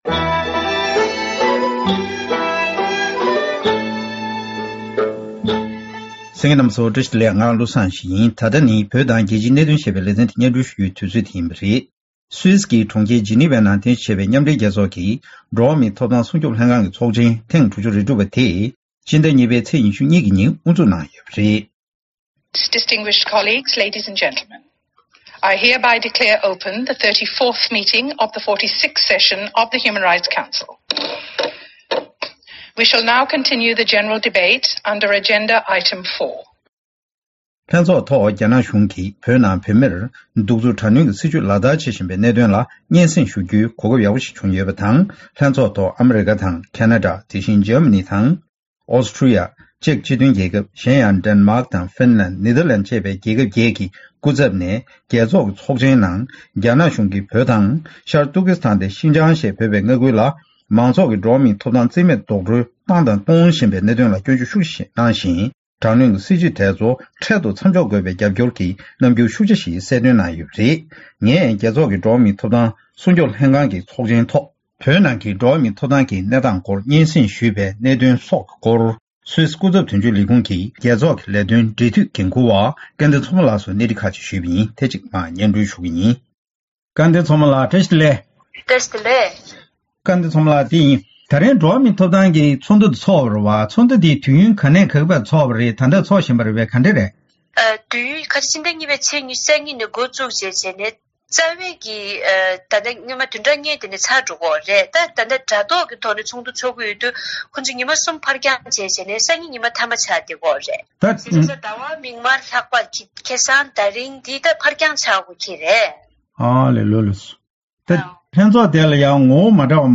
གླེང་མོལ་ཞུས་པ་གསན་རོགས་གནང་།